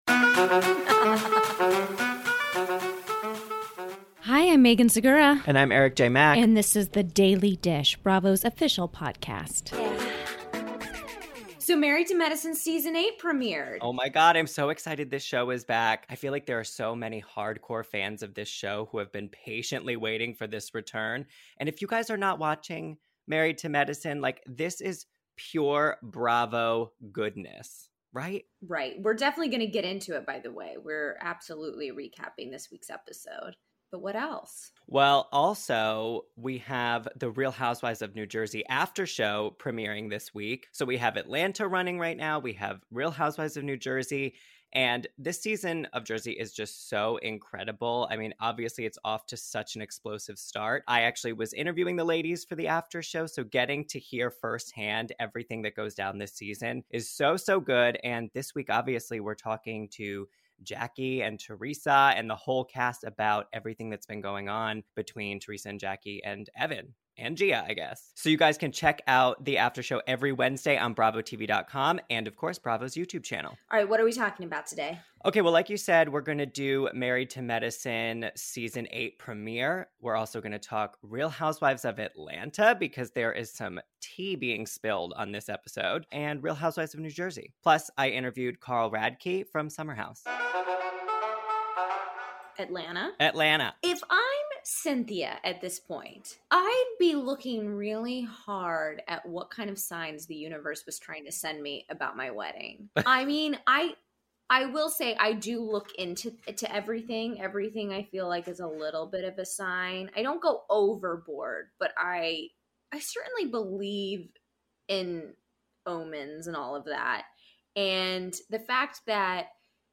Plus, Summer House’s Carl Radke calls in to share how he and his family are coping with the loss of his brother and what it was like to come back to all the housemate drama in the Hamptons.